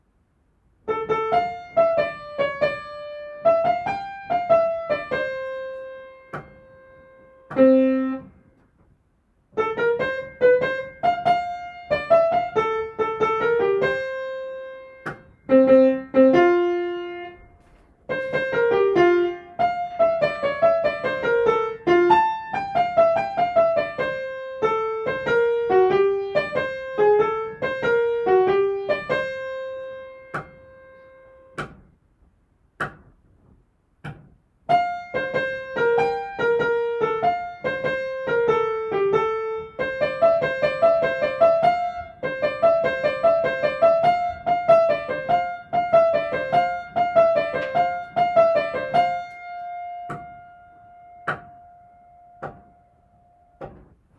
音とり音源
ソプラノ